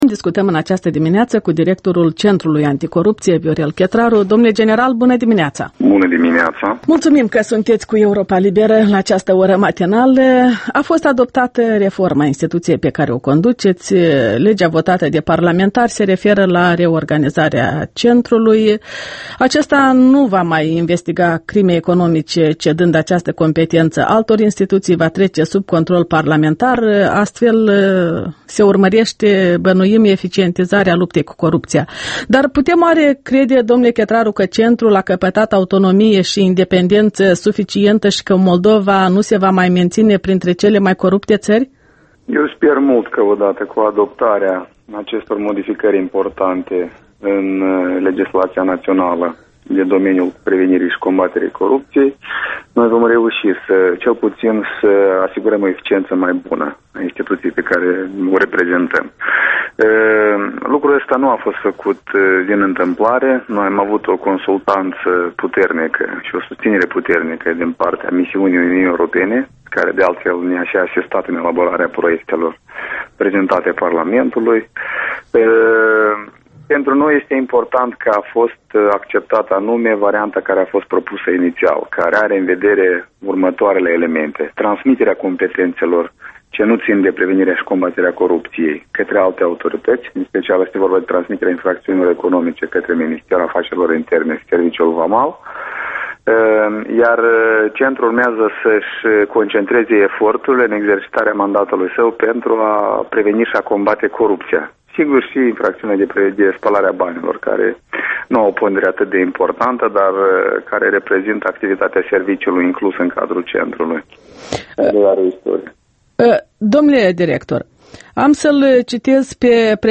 Interviul dimineții la EL: cu Viorel Chetraru despre problema corupției în Moldova